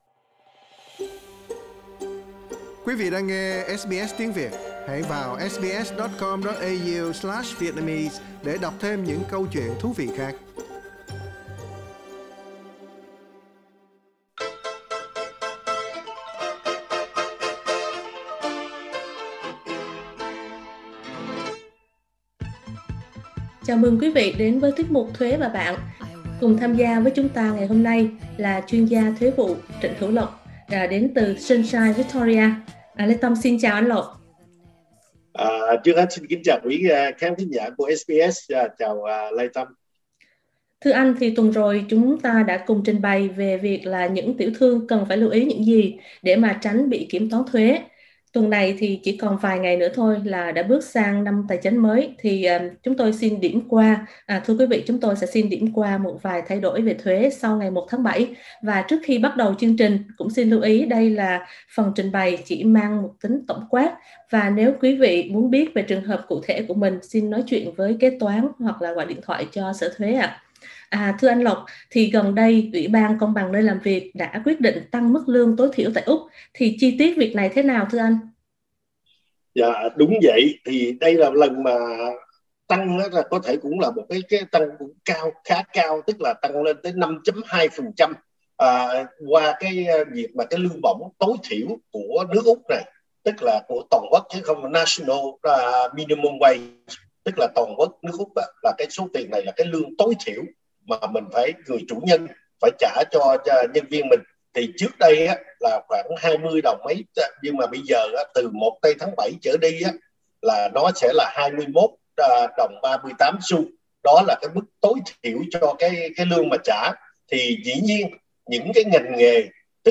bài phỏng vấn